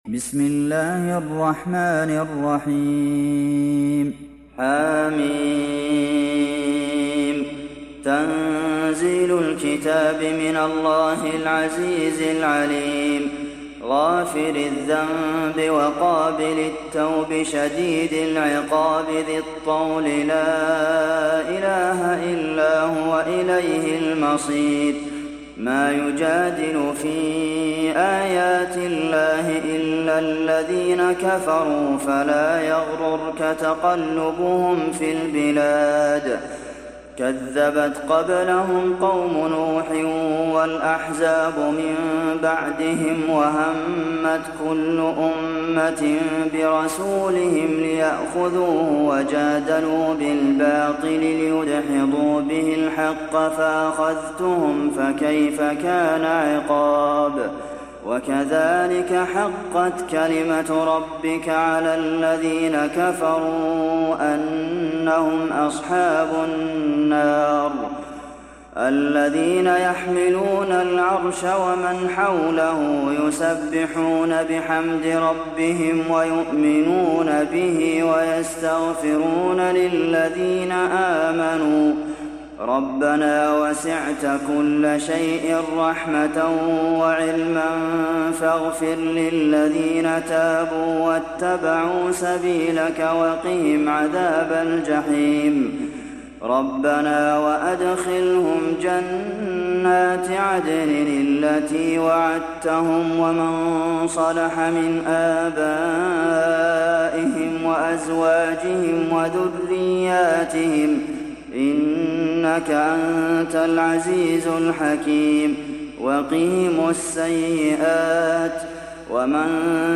دانلود سوره غافر mp3 عبد المحسن القاسم روایت حفص از عاصم, قرآن را دانلود کنید و گوش کن mp3 ، لینک مستقیم کامل